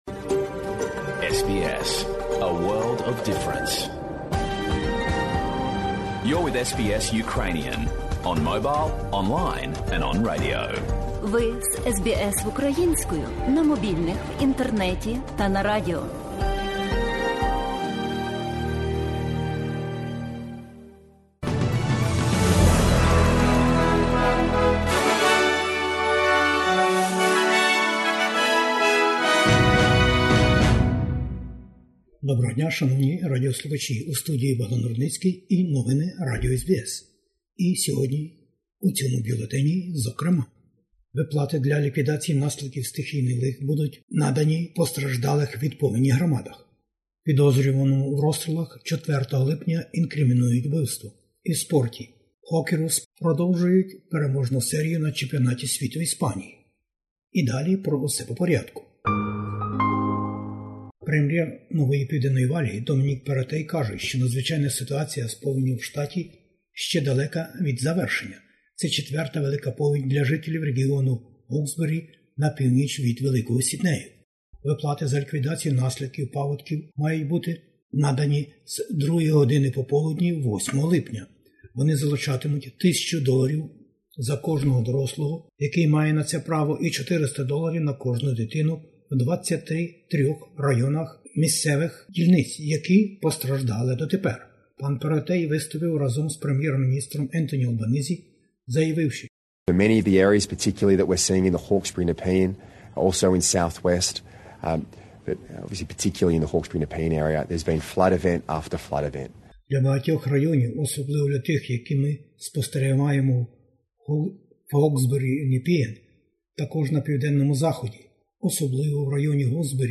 Бюлетень SBS новин українською мовою. Повені у НПВ - очільники влади відвідують потерпілі громади, а урядові допомогові виплати потерпілим будуть виплачені за день-два.